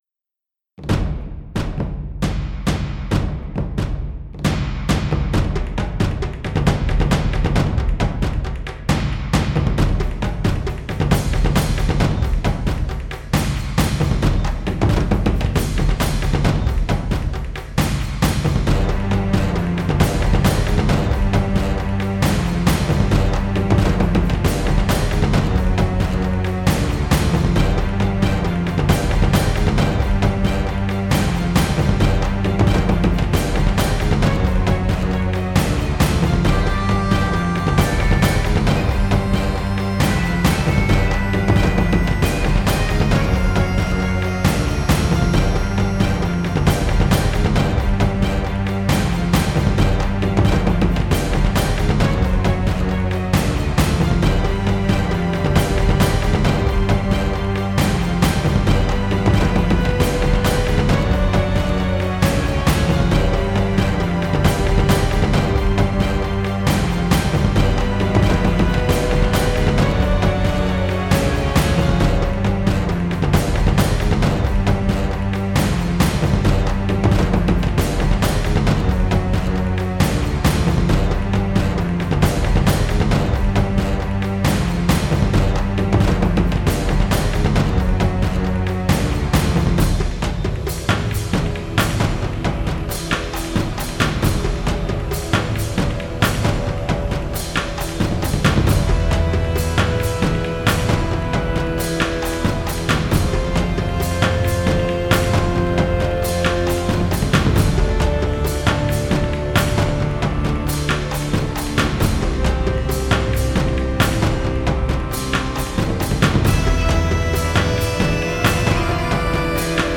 Fight Theme